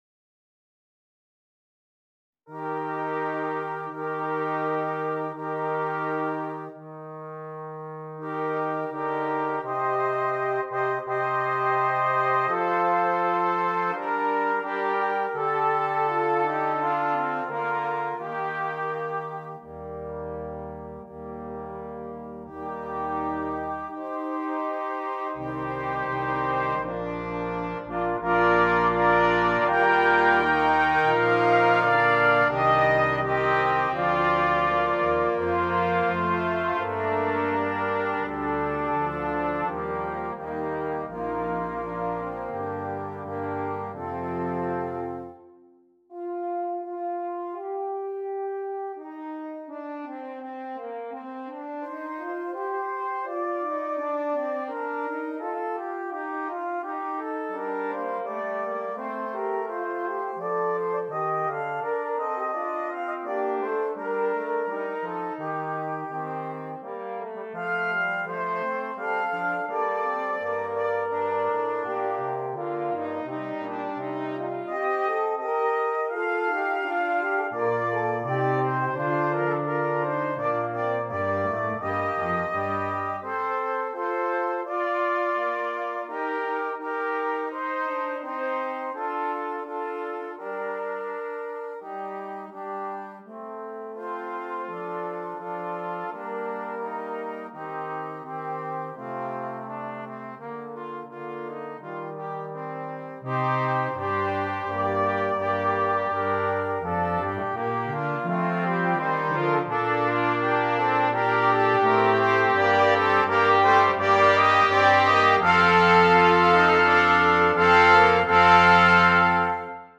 Brass Choir (2.2.2.0.1)